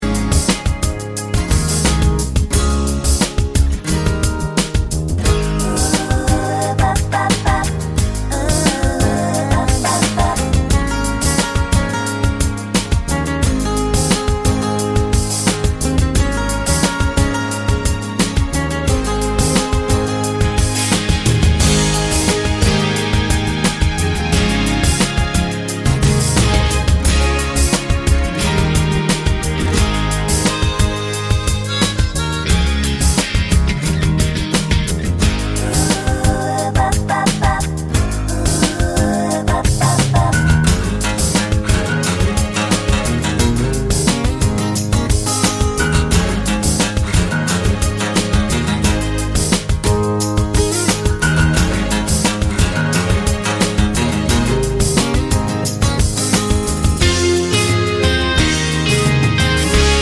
For Duet Indie / Alternative 3:35 Buy £1.50